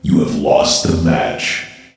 youlose.ogg